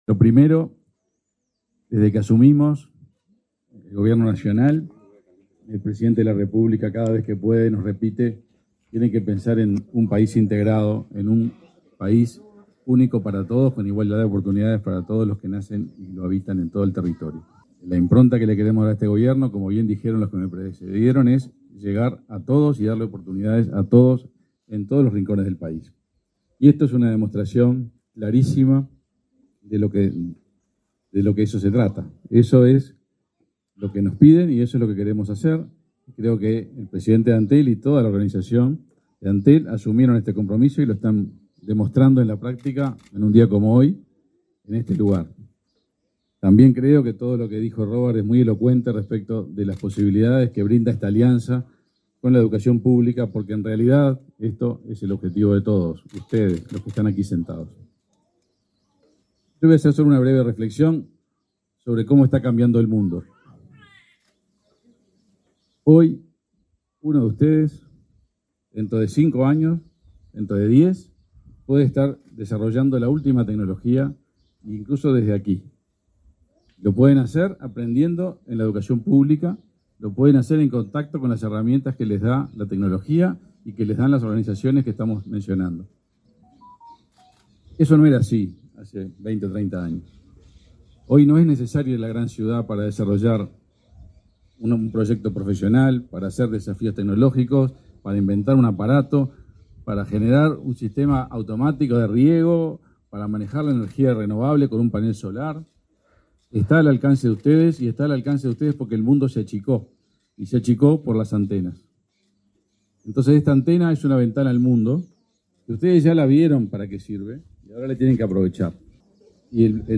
Palabras del ministro de Industria y del presidente del Codicen de la ANEP
El ministro de Industria, Omar Paganini, y el presidente el Consejo Directivo Central (Codicen) de la Administración Nacional de Educación Pública
Palabras del ministro de Industria y del presidente del Codicen de la ANEP 21/12/2021 Compartir Facebook X Copiar enlace WhatsApp LinkedIn El ministro de Industria, Omar Paganini, y el presidente el Consejo Directivo Central (Codicen) de la Administración Nacional de Educación Pública (ANEP), Robert Silva, participaron este martes 21 en Tacuarembó, de la inauguración de radiobases en ese departamento.